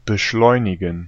Ääntäminen
US : IPA : [əkˈsel.ə.reɪt]